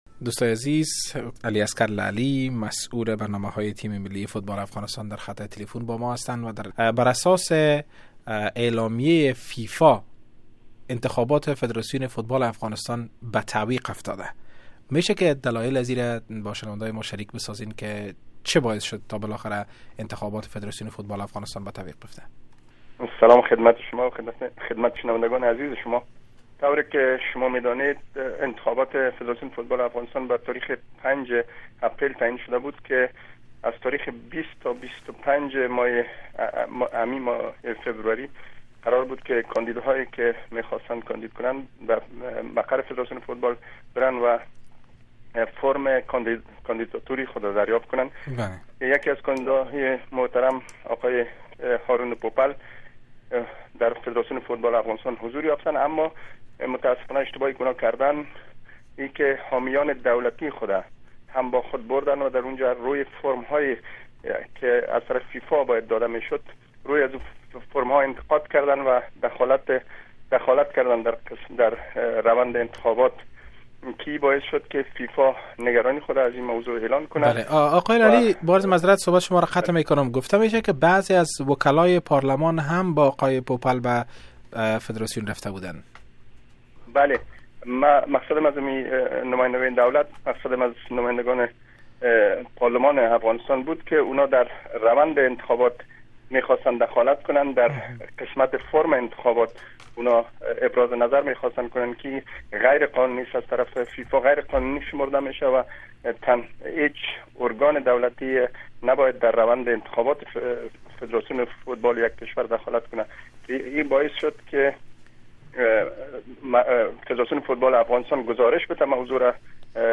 جریان مصاحبه